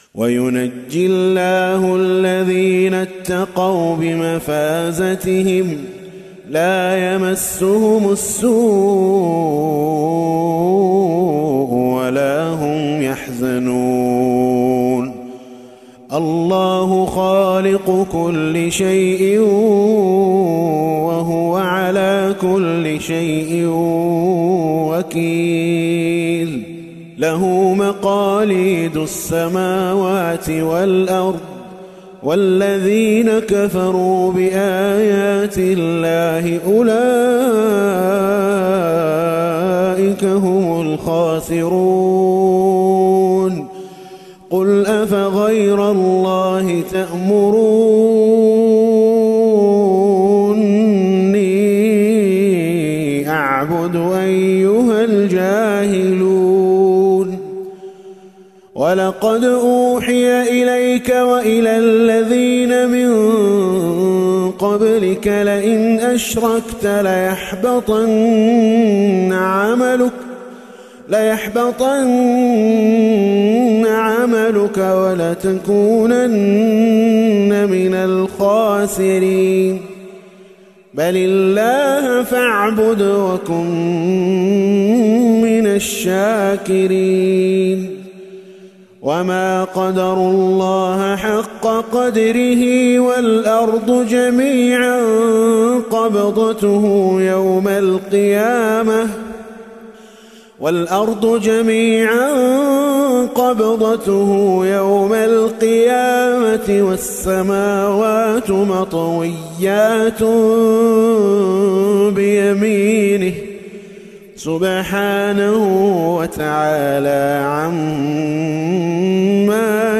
تلاوة خاشعة من سورة الزمر للقارئ